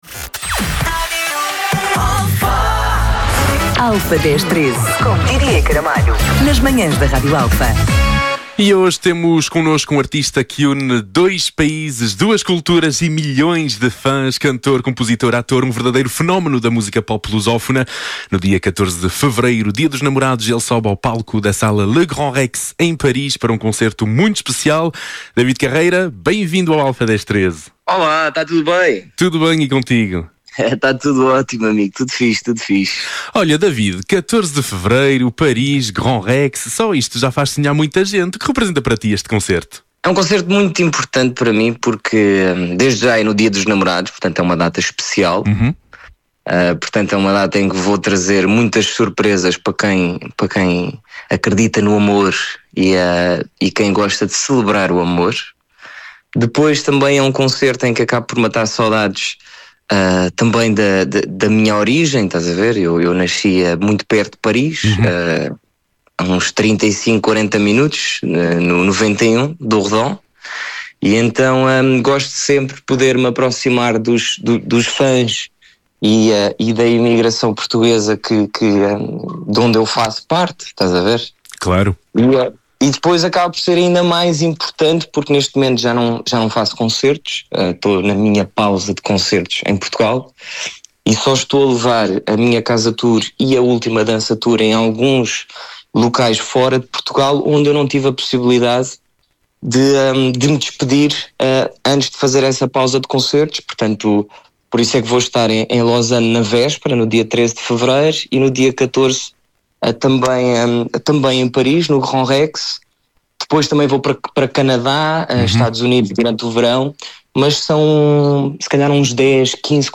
Entrevista-David-Carreira.mp3